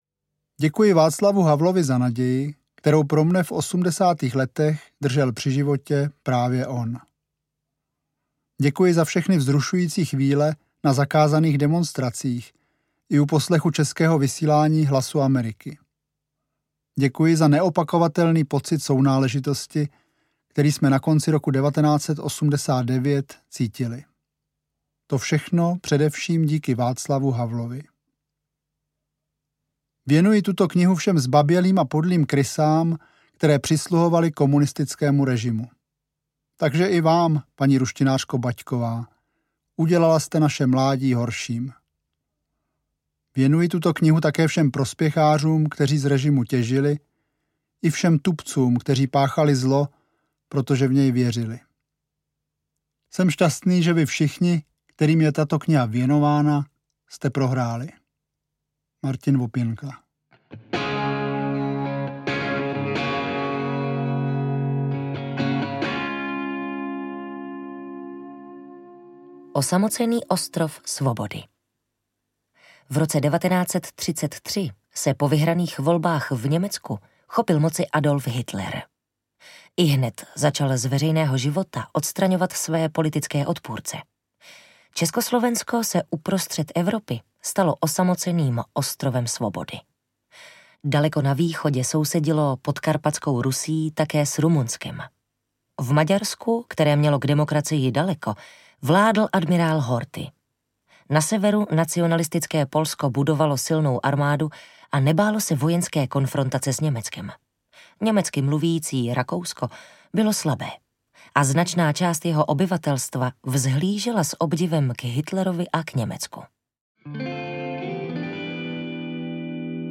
Ukázka z knihy
vaclav-havel-mocny-bezmocny-ve-20-stoleti-audiokniha